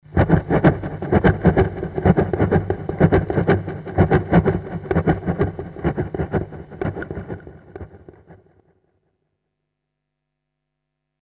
Il treno   mp3 11'' 176 Kb
treno.mp3